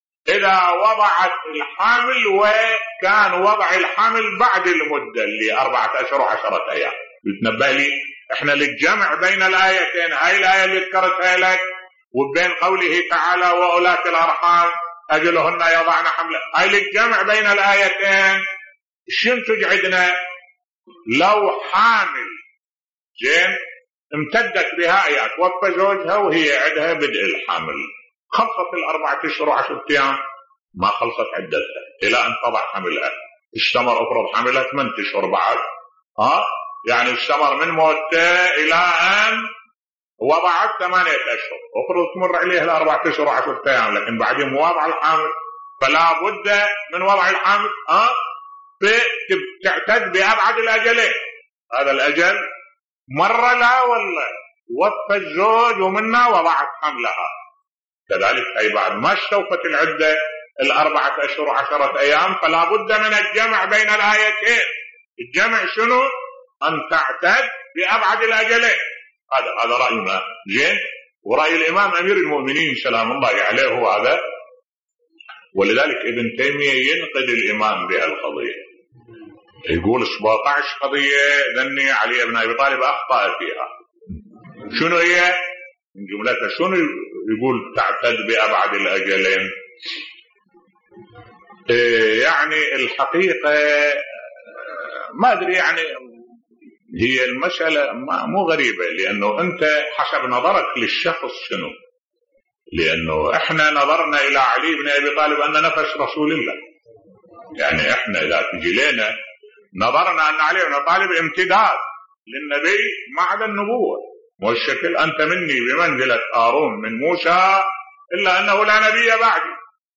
ملف صوتی يرد على تخطئة ابن تيميه للامام علي في عدة الحامل بصوت الشيخ الدكتور أحمد الوائلي